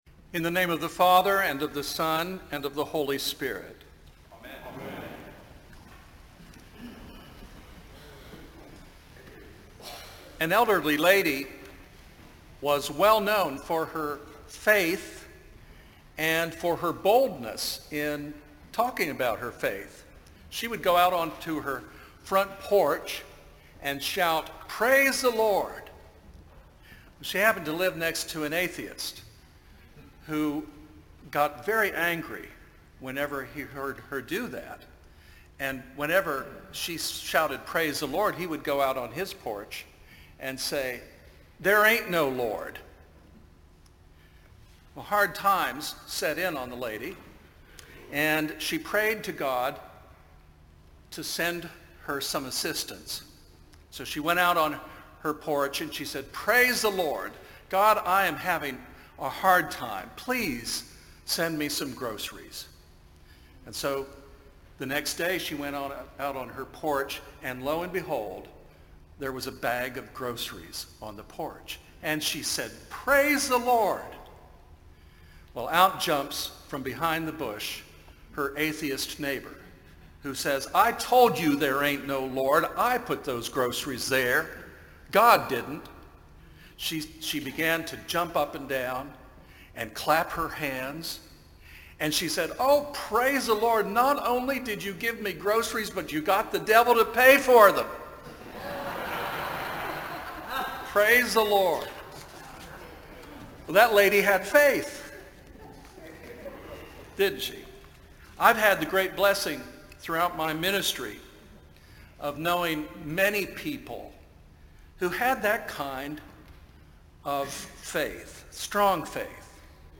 On the Third Sunday after Pentecost
preaches on faith and belief